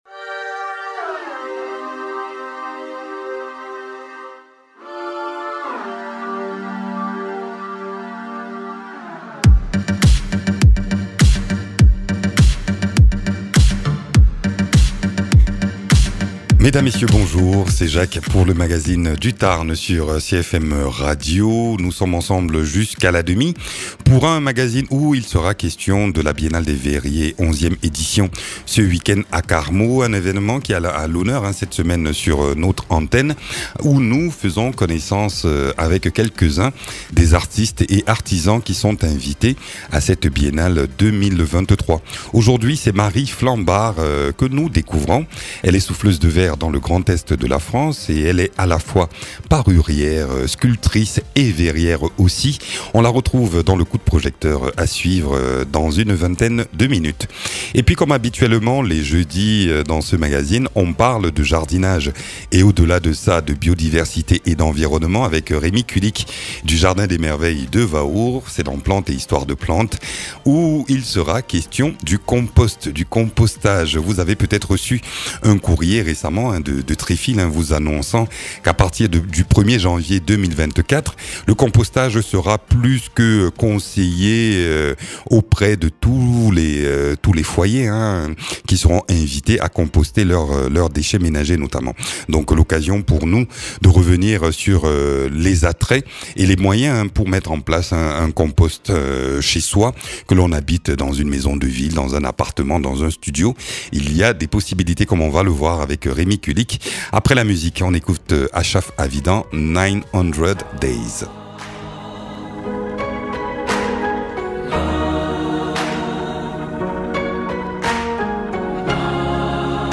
Aussi, nous faisons connaissance avec une souffleuse de verre invitée ce weekend à Carmaux pour la biennale des verriers.